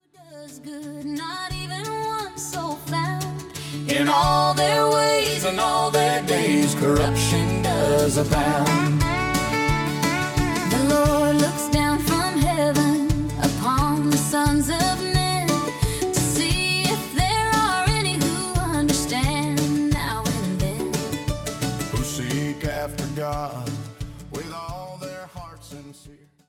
authentic Country worship
the authentic sound of modern Country worship
From intimate acoustic moments to full-band celebrations